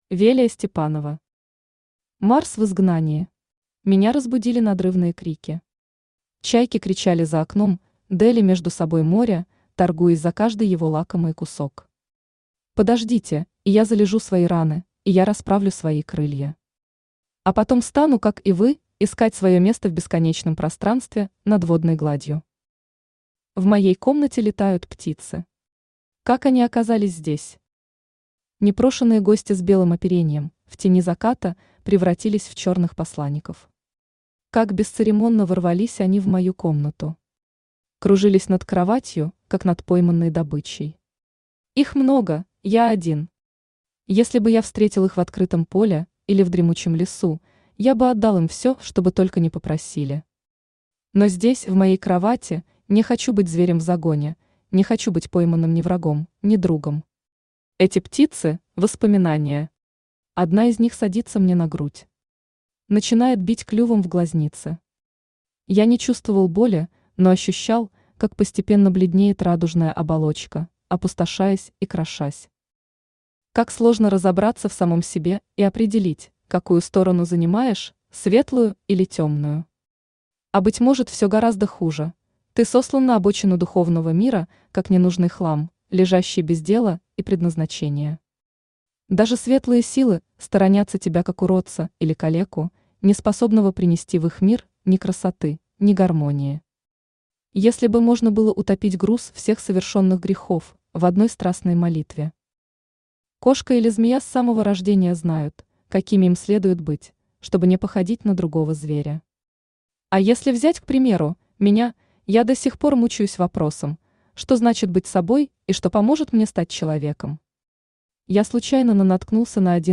Аудиокнига Марс в изгнании | Библиотека аудиокниг
Aудиокнига Марс в изгнании Автор Велия Степанова Читает аудиокнигу Авточтец ЛитРес.